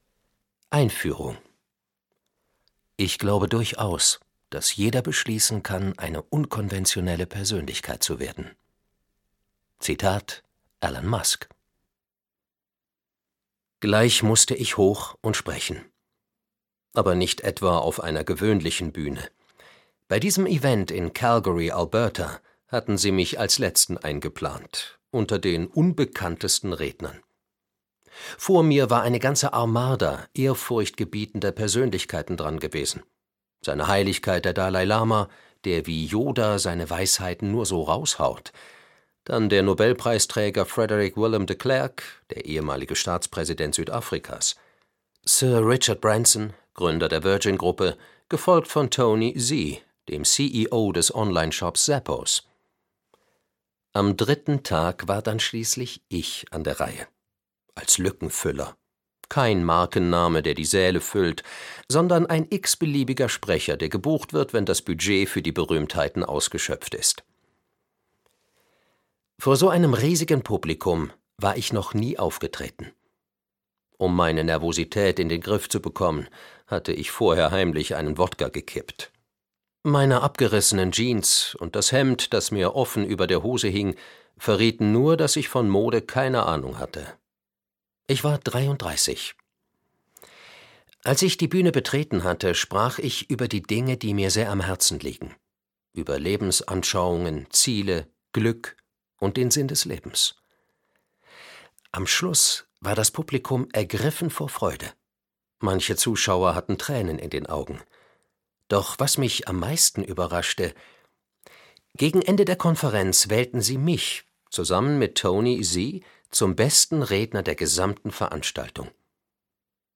Lebe nach deinen eigenen Regeln - Vishen Lakhiani - Hörbuch